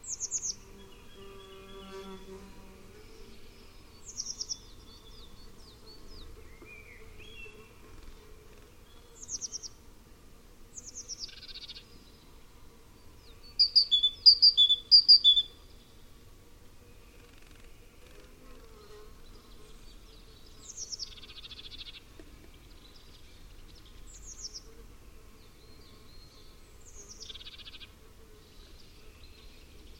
Photos de Mésange charbonnière - Mes Zoazos